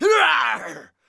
dead_1.wav